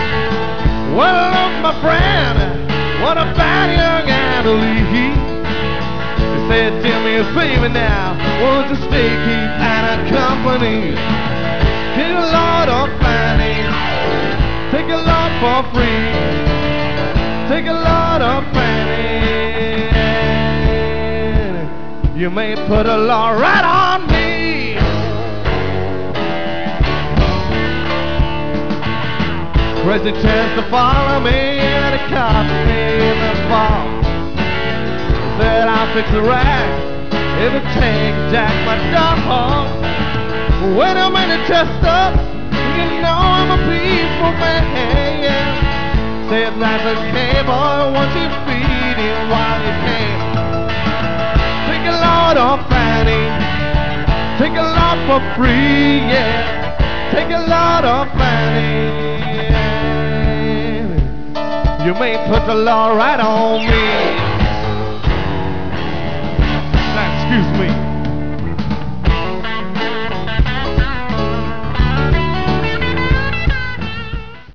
four-piece ensemble